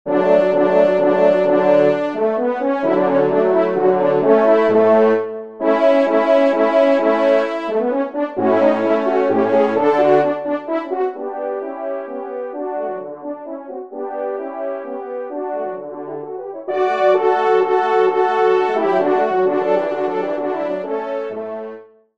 24 compositions pour Trio de Cors ou de Trompes de chasse